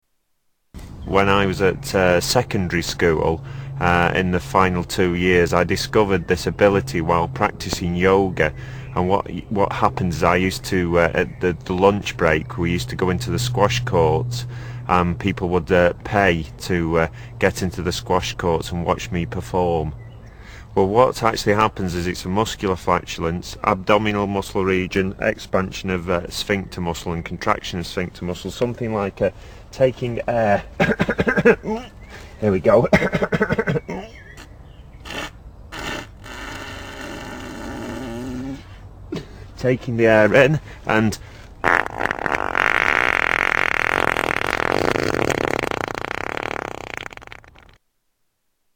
Category: Comedians   Right: Personal
Tags: Comedians Mr Methane Fart Fart Music Paul Oldfield